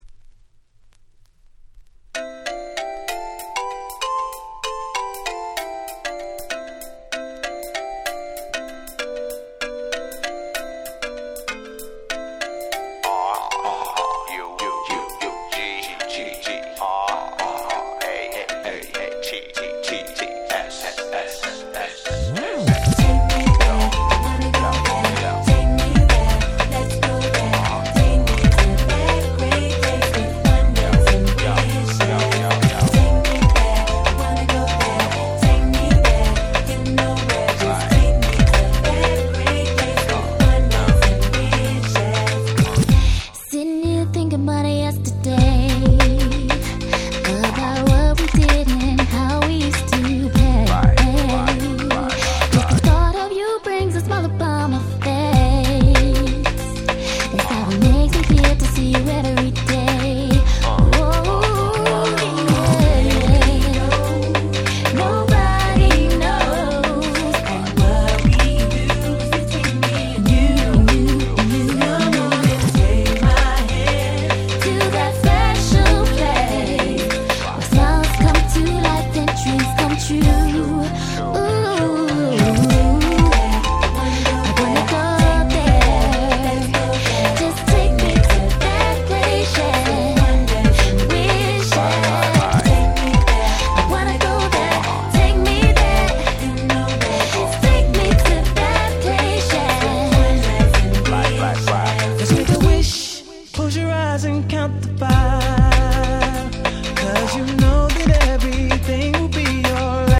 99' Super Hit R&B !!